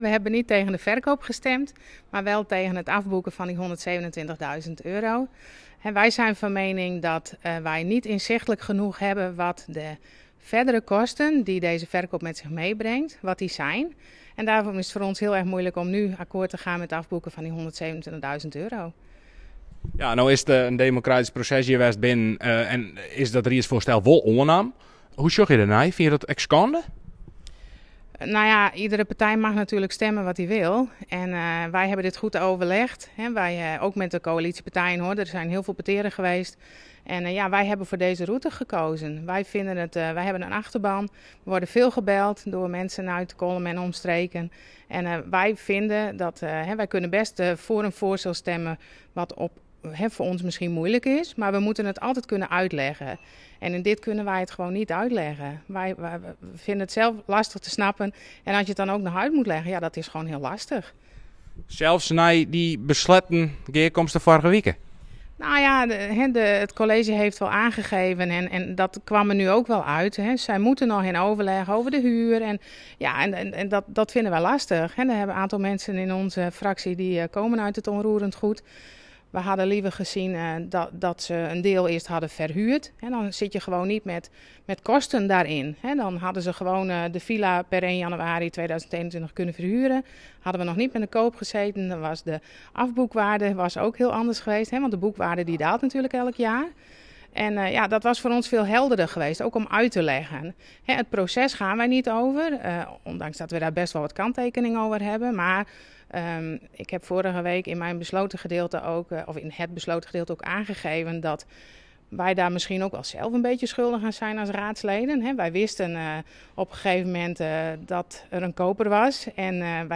Digna Ferwerda, Fractievoorzitter VVD